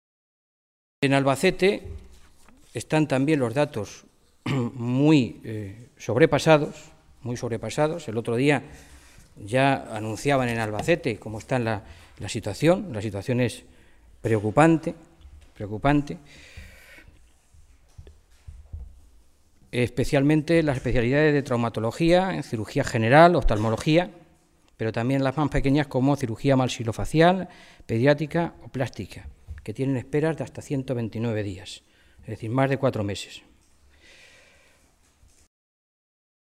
Fernando Mora, portavoz de Sanidad del Grupo Socialista
Cortes de audio de la rueda de prensa